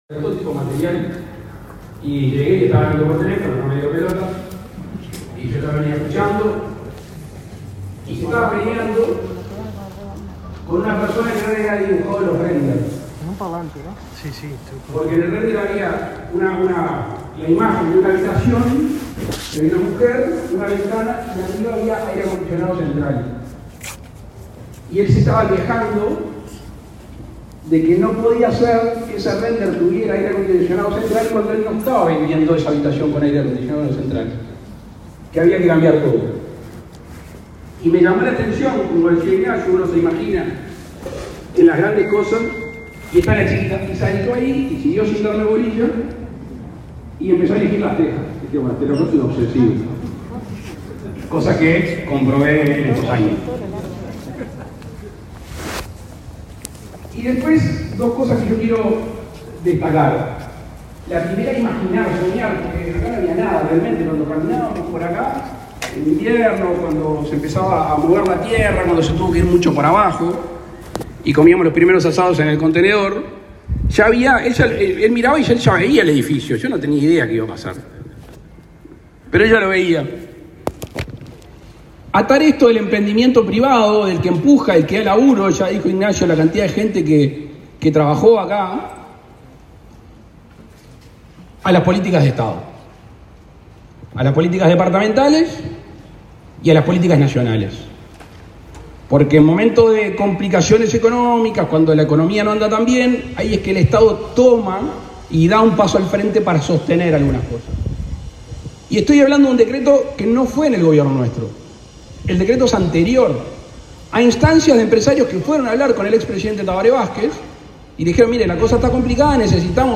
Palabras del presidente Luis Lacalle Pou
El presidente Luis Lacalle Pou participó este martes 28 de la inauguración del residencial Fendi Chateu en Punta del Este, Maldonado.